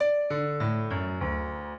piano
minuet15-10.wav